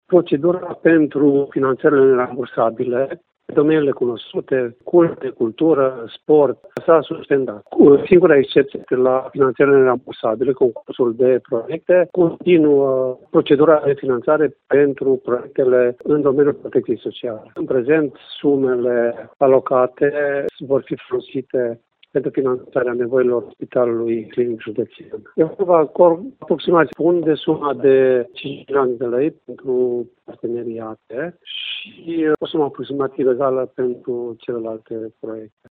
Singurele proiecte care vor mai fi finanțate de către Consiliul Județean vor fi cele de asistență socială, spune vicepreședintele acestuia, Alexandru Cîmpeanu